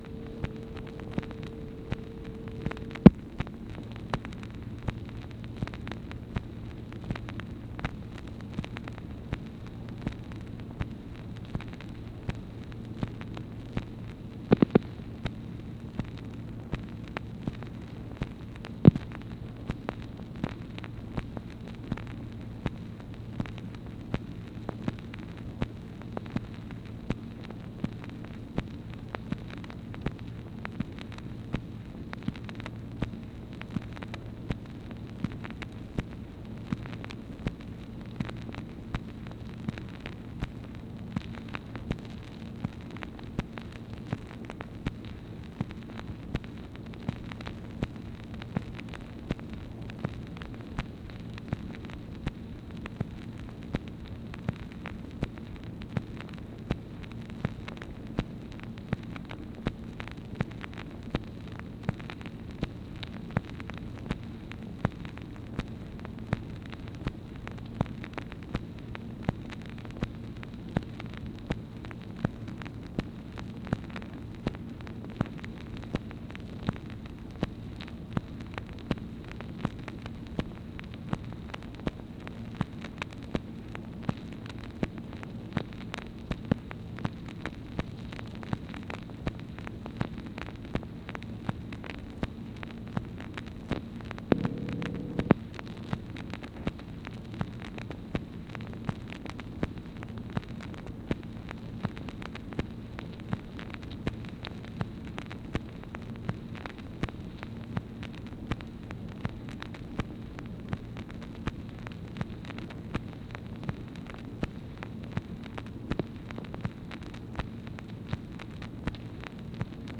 MACHINE NOISE, April 25, 1964
Secret White House Tapes | Lyndon B. Johnson Presidency